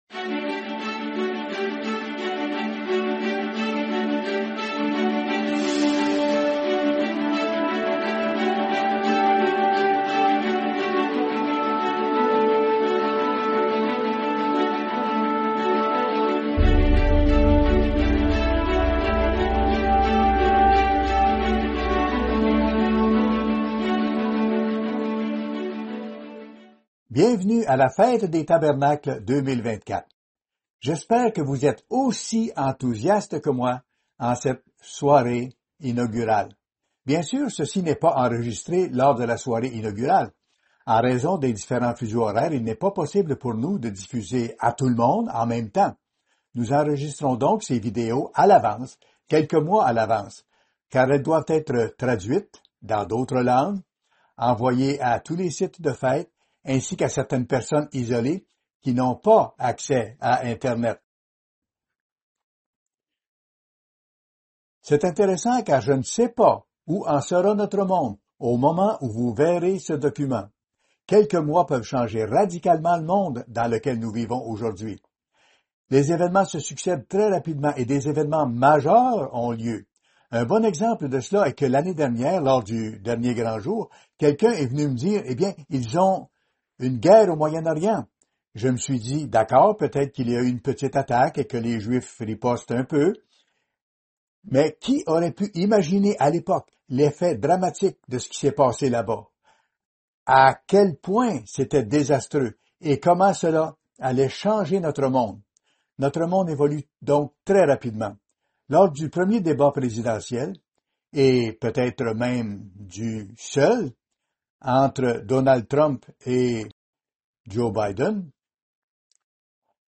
Soirée inaugurale de la Fête des Tabernacles 2024